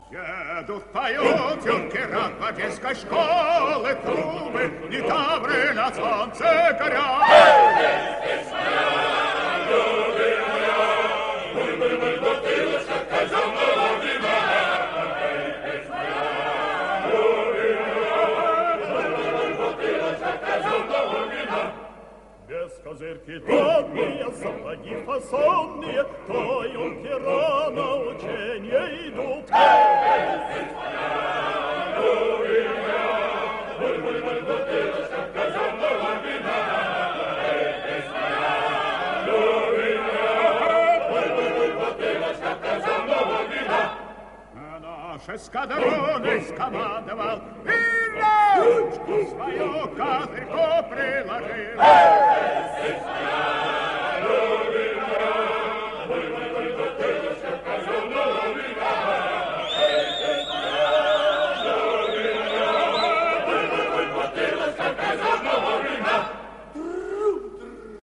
Но поют они здесь поистине с юношеским задором…